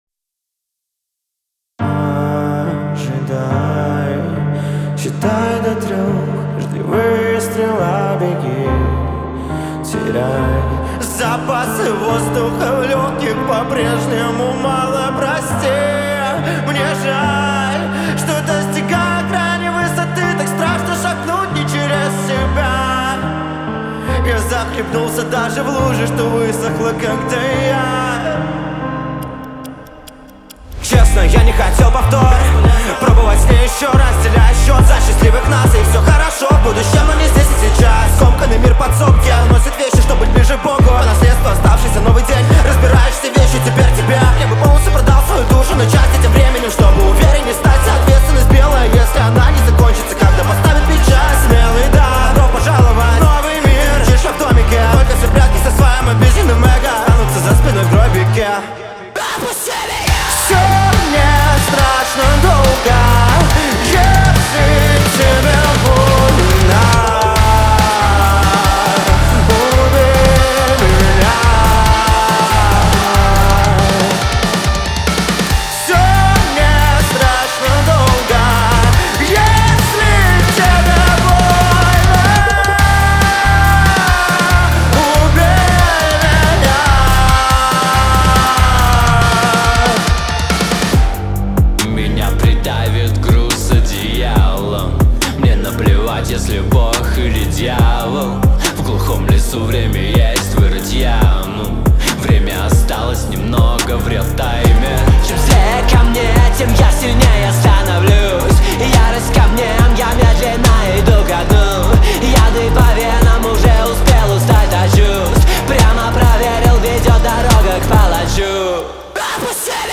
Категории: Русские песни, Альтернатива.